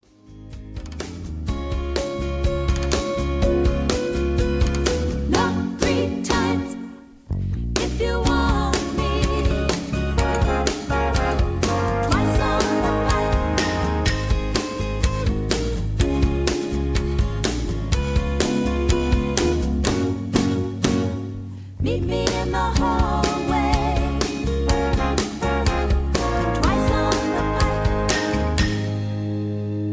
backingtracks , karaoke